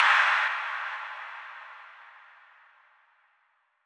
NoiseImpactBurst.wav